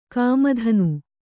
♪ kāma dhanu